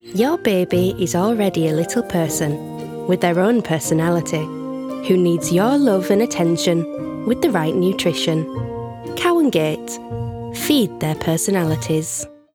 Northern
Warm, Trusting, Friendly, Reassuring